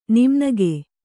♪ nimnage